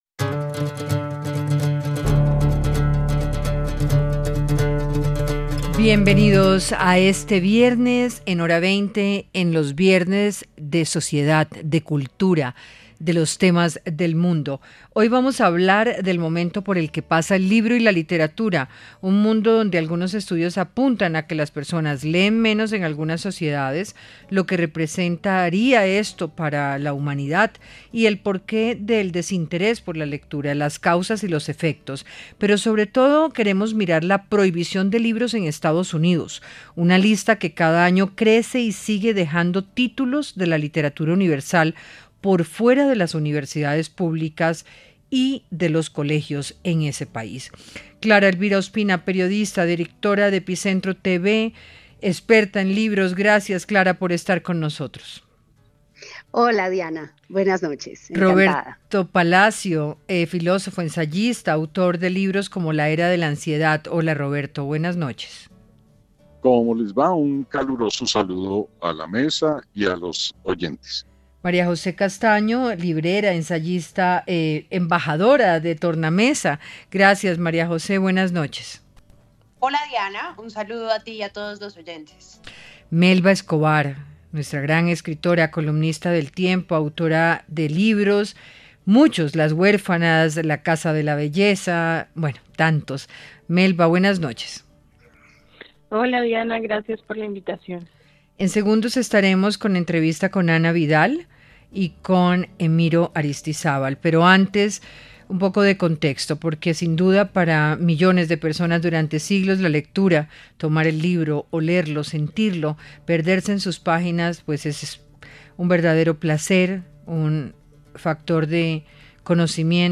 Panelistas analizaron lo que implica vivir en una sociedad que ha prohibido más de 21 mil libros y la caída en niveles de lectura en Estados Unidos.